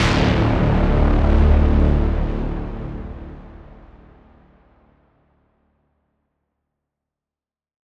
quest_start.wav